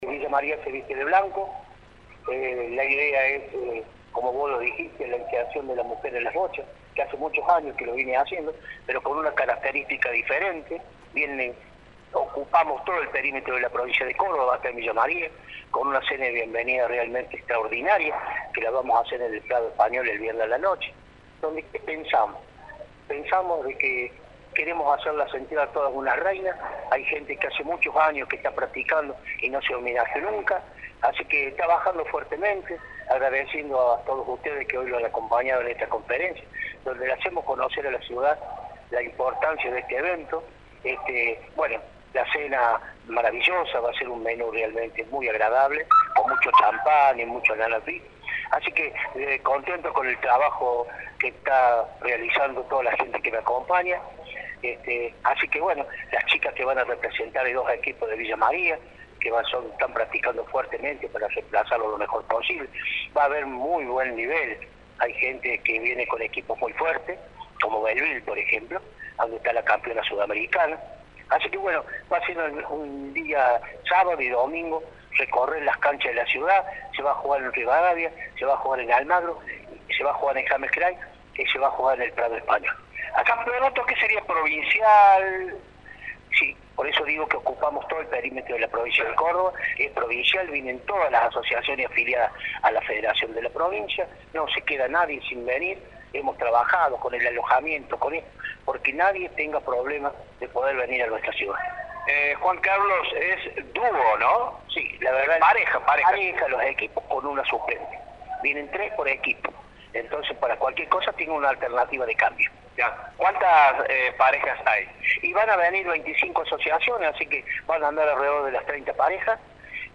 en la conferencia de prensa realizada este miércoles